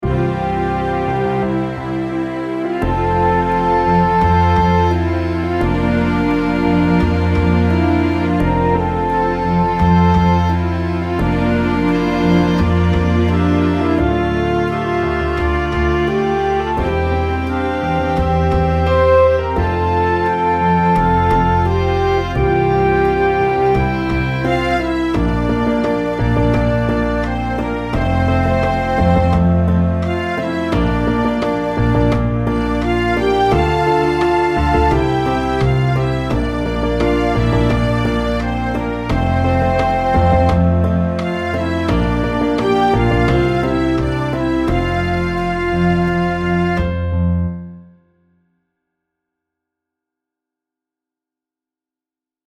Genre : Anthem